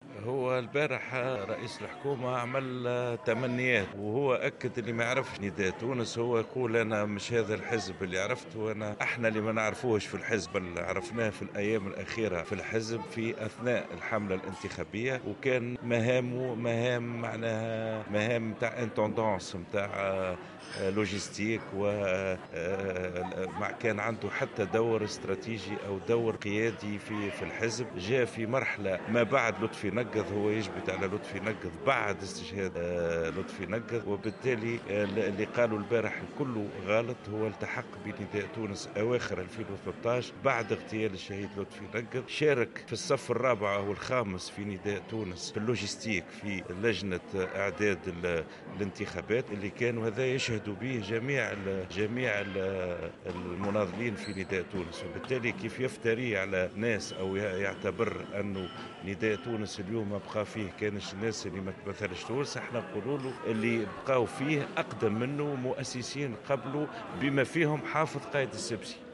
وجاءت تصريحاته على هامش أشغال الندوة الوطنية للمنسقين الجهويين لحركة نداء تونس المنعقدة حاليا في المهدية.